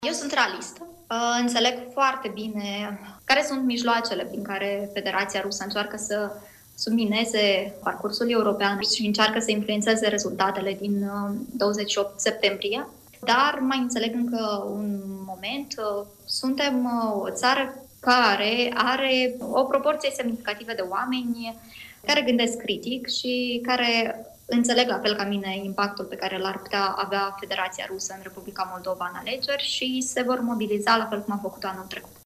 în emisiunea „Imperativ”